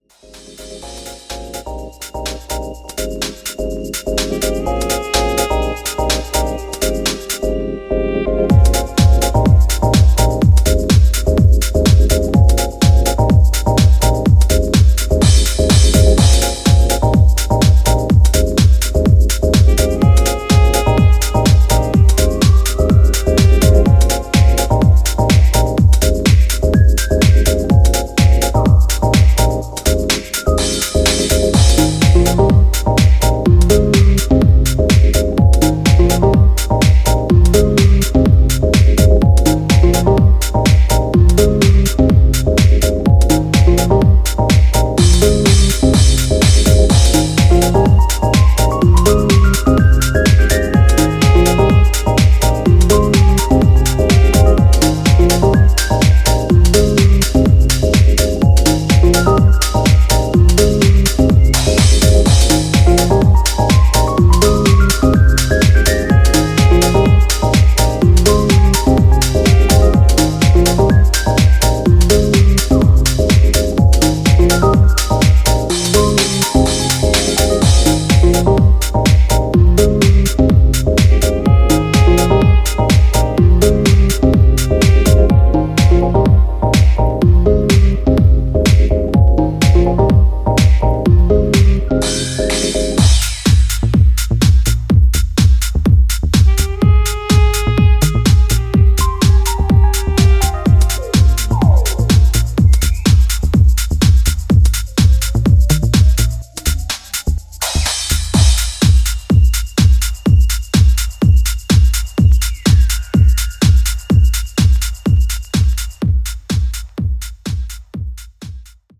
ムーディーなディープ・ハウスを展開していくB面